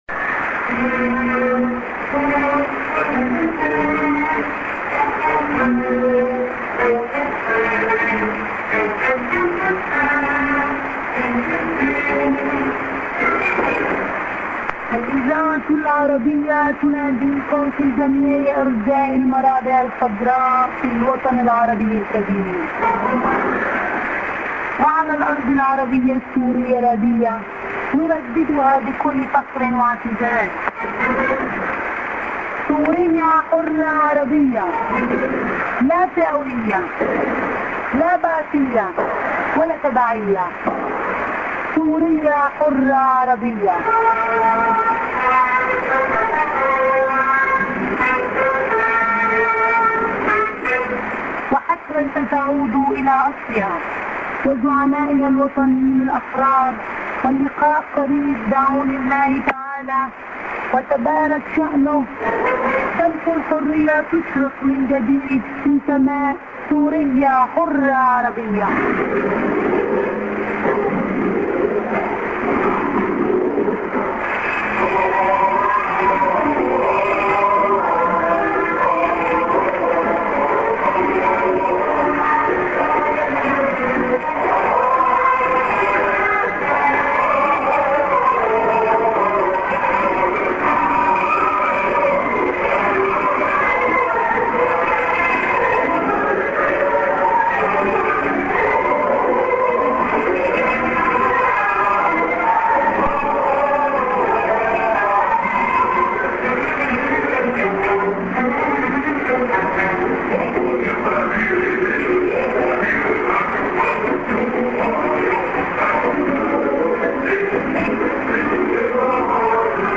St. IS->ID(women)->music->02'45":ANN(women:ID+SKJ+Web ADDR)->music->